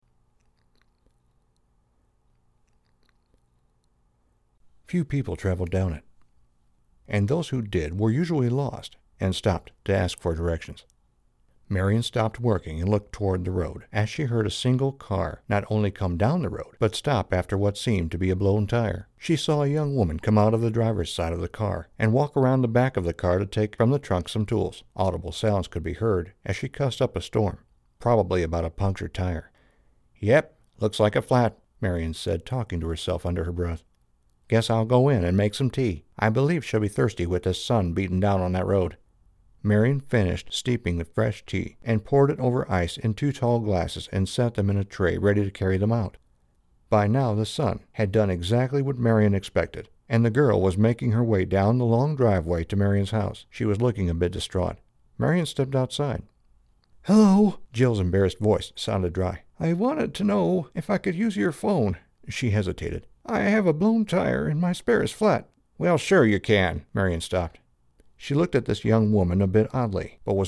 Демо аудиокнига Категория: Аудио/видео монтаж